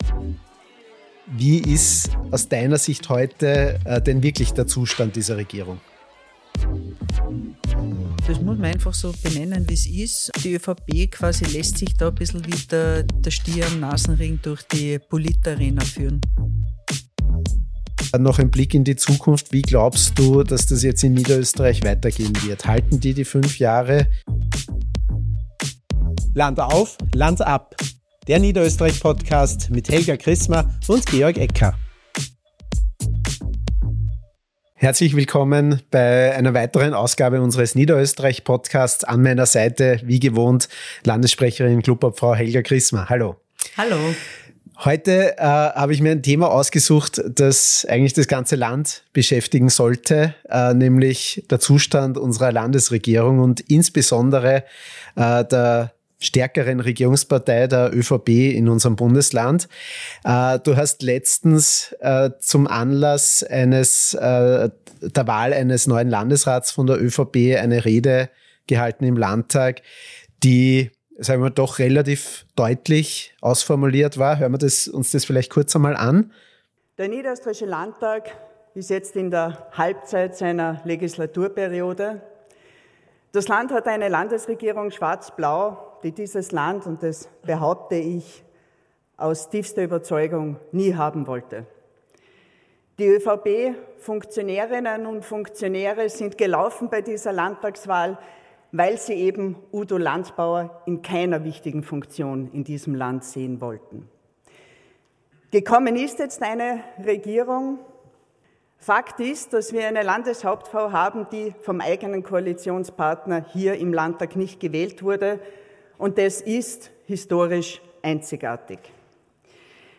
Warum verliert die Partei zunehmend an Führungskompetenz, warum bleiben Loyalitäten brüchig und wie kam es zu den aktuellen Personalrochaden? Klubobfrau Helga Krismer spricht mit LAbg. Georg Ecker über schwarze Fehlentscheidungen und Machtspiele. Wir analysieren den scheinbar unaufhaltsamen Zerfallsprozess der NÖVP, den Einfluss der Blauen und die Kritik an den Sparmaßnahmen und Reformen – bis hin zu brisanten Themen rund um Postenvergabe und möglichen Amtsmissbrauch.